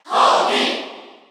Category: Crowd cheers (SSBU) You cannot overwrite this file.
Kirby_Cheer_Korean_SSBU.ogg.mp3